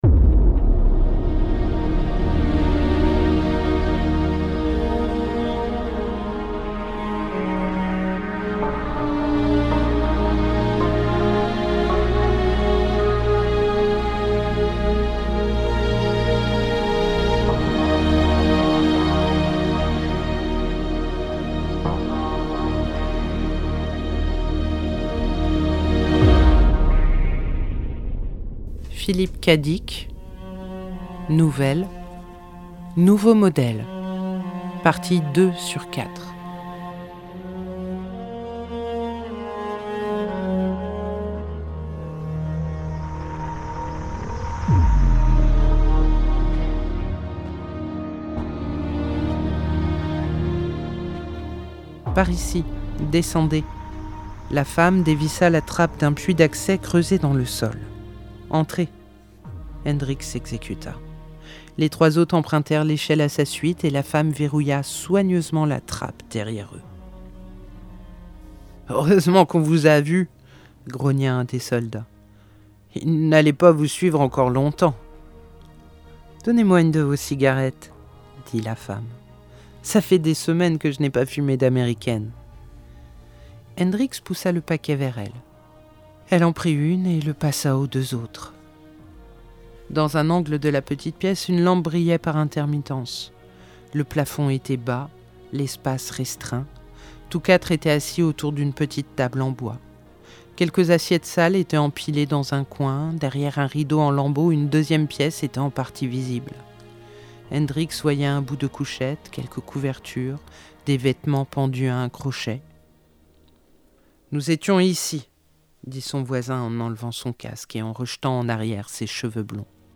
🎧 Nouveau Modèle – Philip K. Dick - Radiobook
Nouvelle, partie 2/4 (39:13)